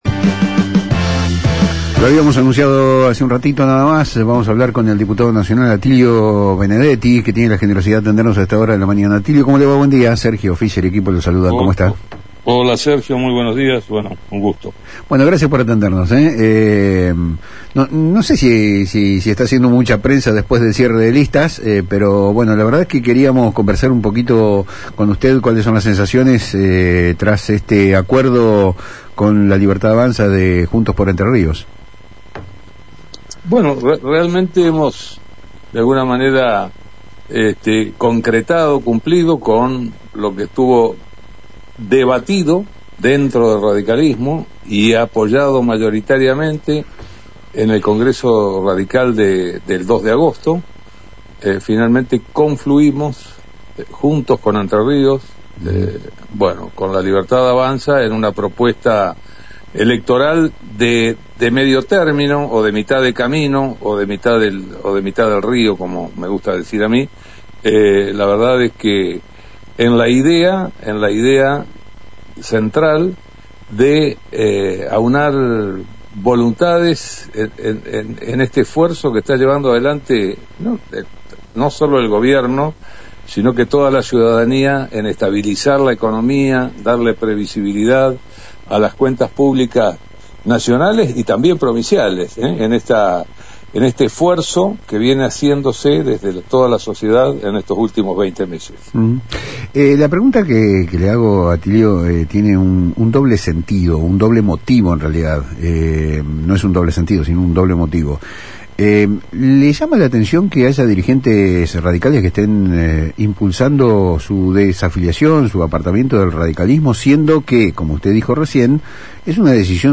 En una entrevistaconcedida a Palabras Cruzadas por FM Litoral, Benedetti se refirió a las críticas internas de algunos dirigentes radicales que no están de acuerdo con la alianza.